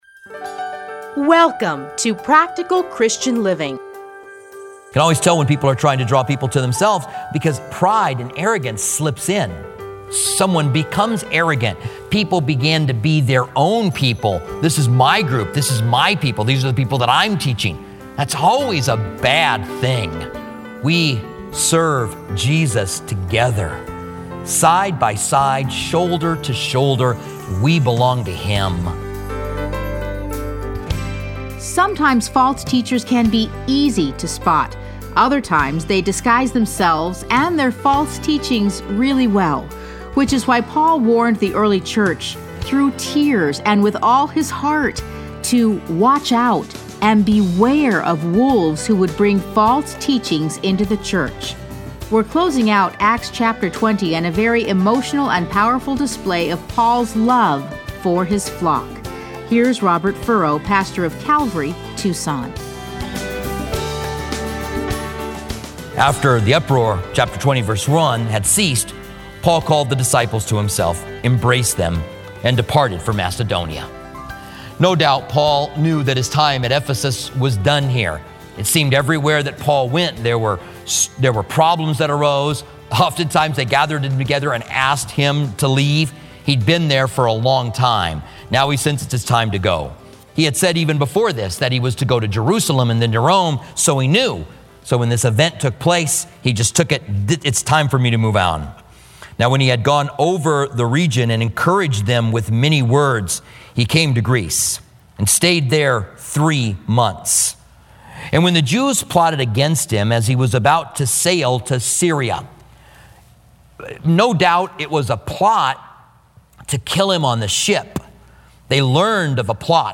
Listen to a teaching from Acts 19:21 to 20:38.